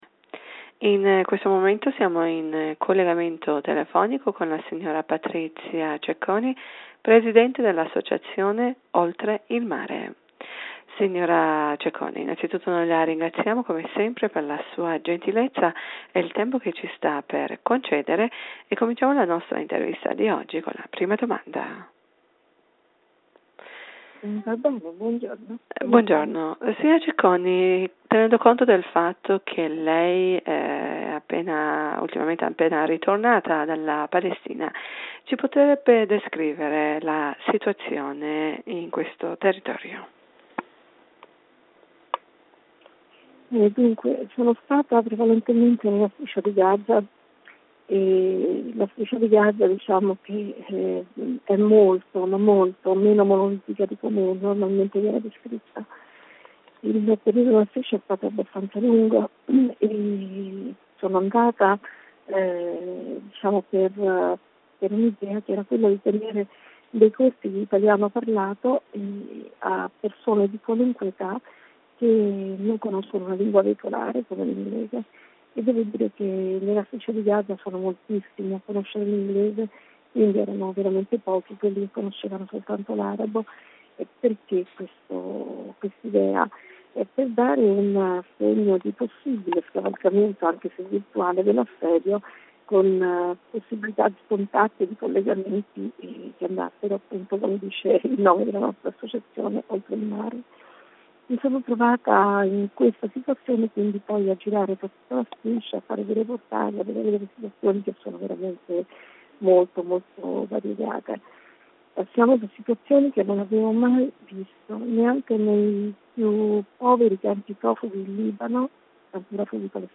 in collegamento telefonico a Radio Italia